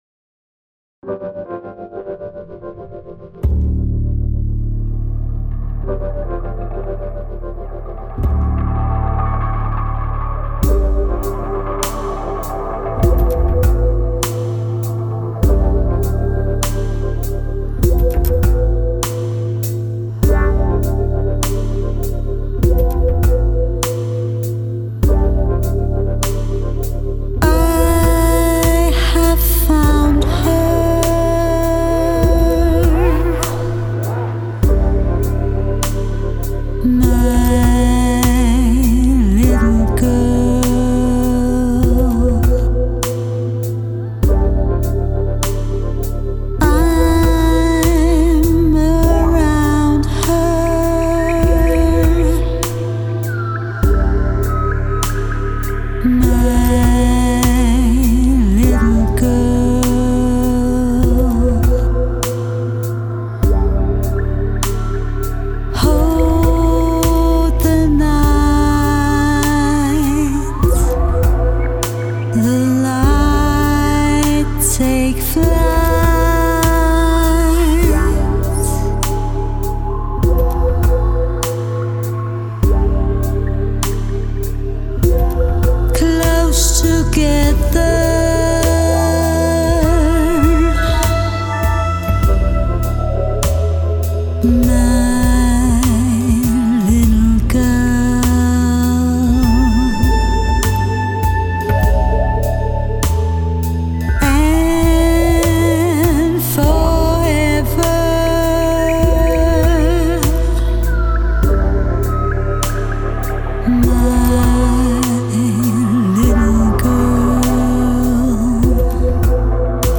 Nostalgique ?
trip hop